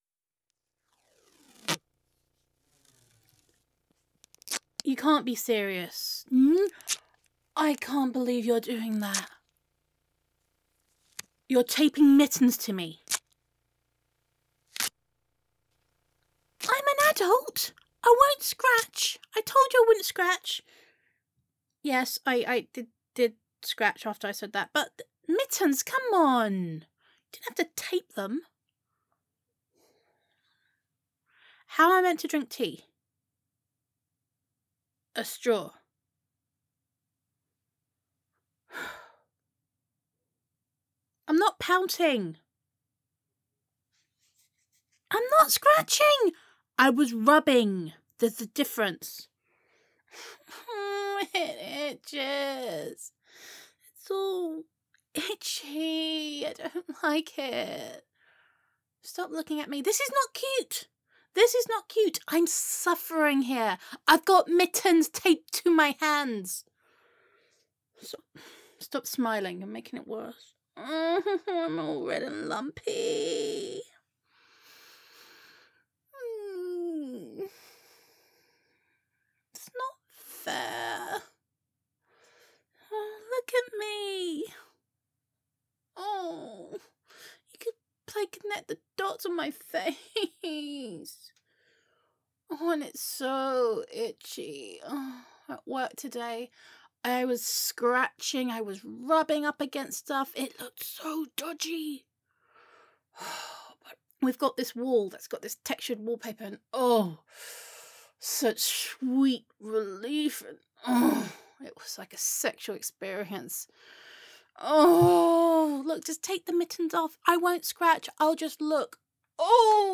Downloads Download [F4A] Mittens [Ridiculous][Whiny Girlfriend][Pouting][You Are So Mean][the Itch Made Me Do It][False Promises][.mp3 Content Just another day being your itchy, scratchy, lovey-dovey hostage. --- Character Type: Itchy Girlfriend Theme or Scenario: A comical struggle between a girlfriend and her over-protective partner who tapes mittens to her hands to prevent scratching. Emotional Tone or Mood: Sarcastic, Comical, Playful Content Type: Audio Roleplay Teaser: Welcome to a world where love is itchy and tea is sipped through straws.